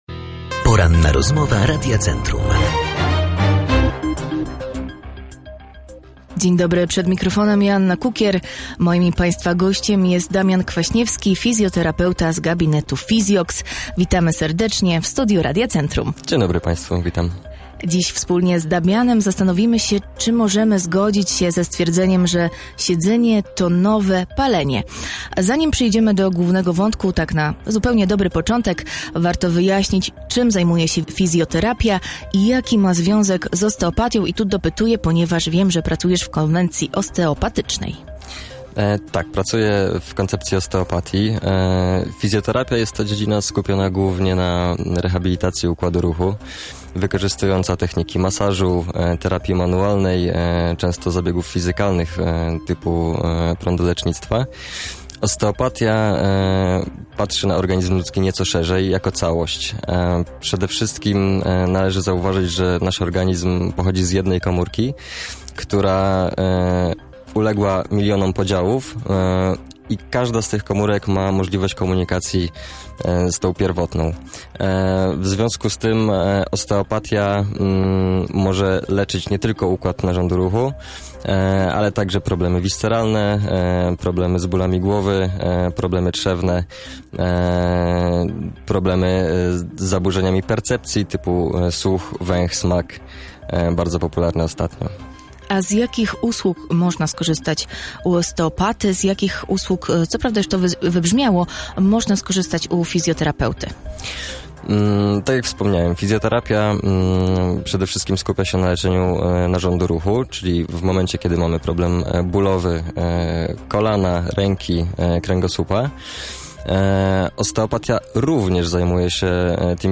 Siedzący tryb życia, brak ruchu, spędzanie całego dnia przed ekranem komputera – to często nasza rzeczywistość. Podczas Porannej Rozmowy Radia Centrum próbowaliśmy odpowiedzieć na pytanie: czy siedzenie to nowe palenie?
Jak przeciwdziałać negatywnym skutkom siedzenia? Tego dowiecie się z poniższej rozmowy: